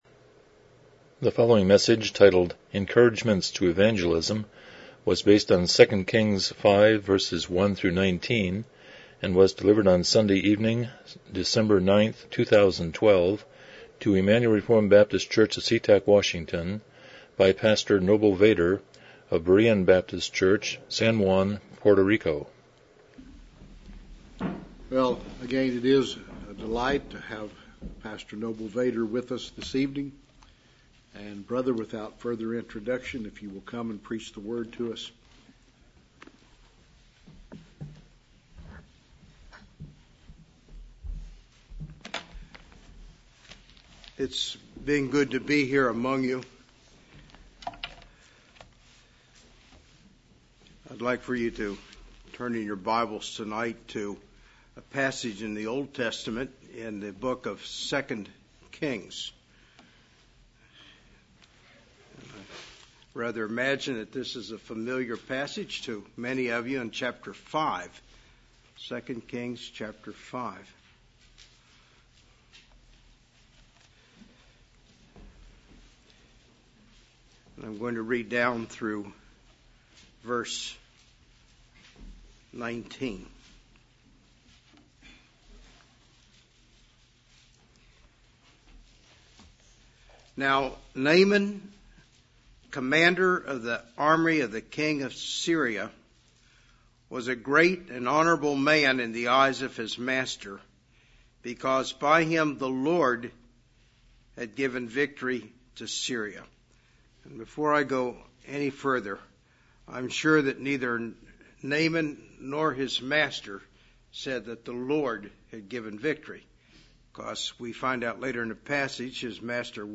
2 Kings 5:1-19 Service Type: Evening Worship « Jesus First Sign